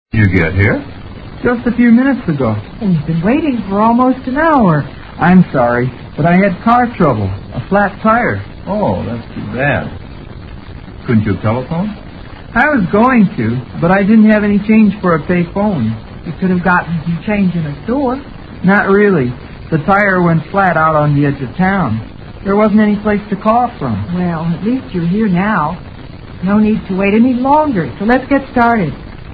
Dialogue 7